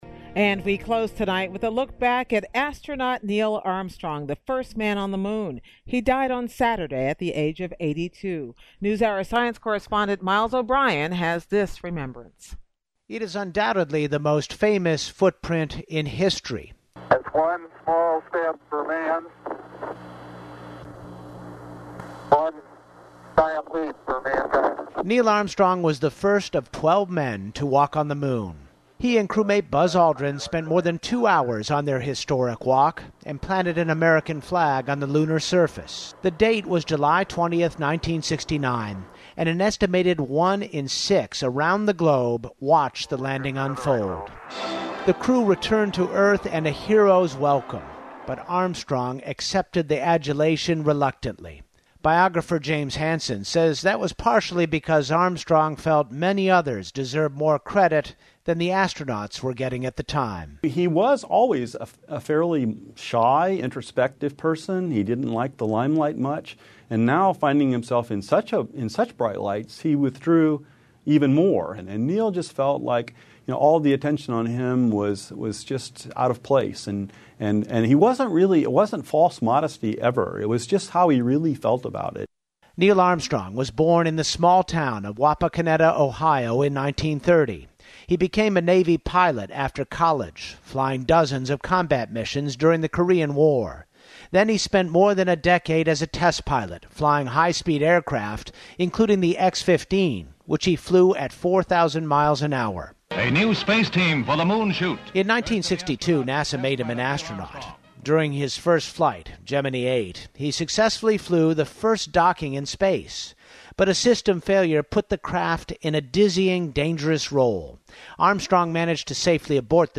英语访谈节目:一小步启发一个国家 最谦逊的英雄尼尔·阿姆斯特朗